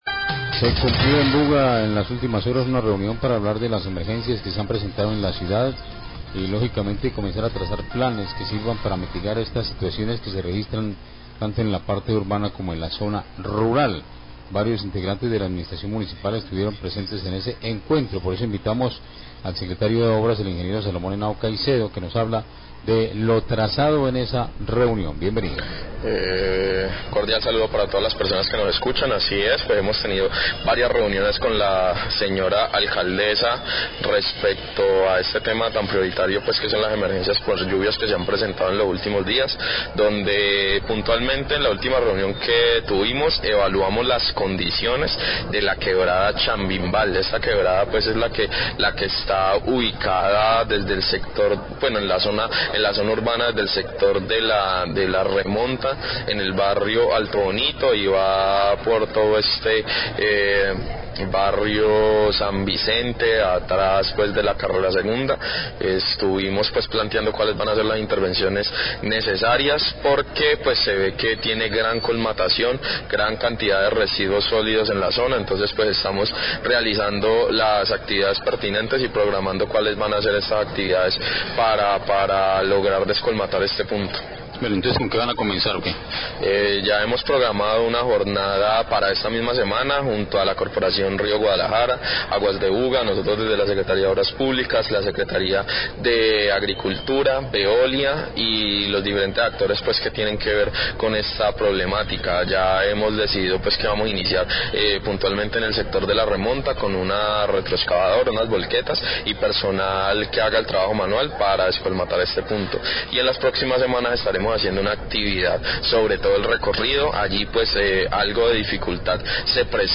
Radio
El Secretario de Obras Públilcas de Buga, Salomón Henao, habla de las acciones que emprenderá la administración municipal para mitigar el impacto de las fuertes lluvias y crecientes súbitas de la acequia Chambinbal cada invierno. En esta semana se llevará a cabo una jornada de descolmatación de la quebrada junto a las entidades competentes.